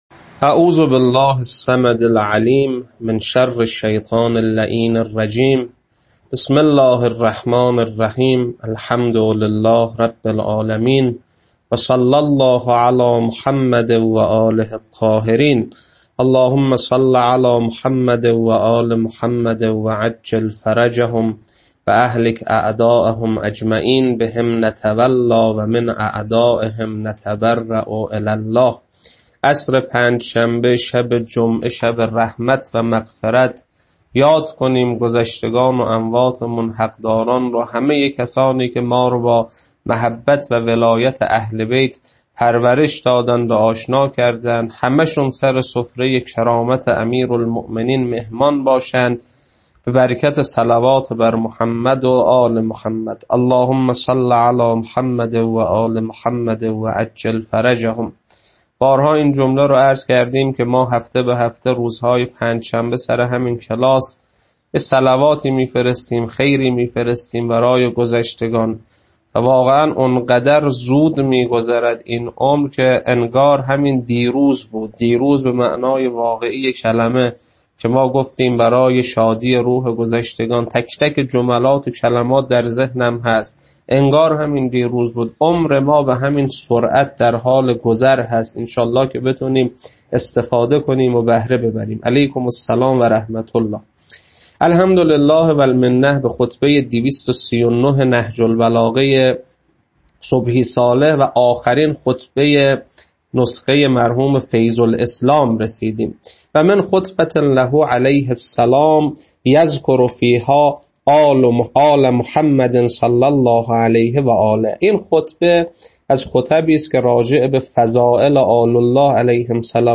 خطبه 239.mp3
خطبه-239.mp3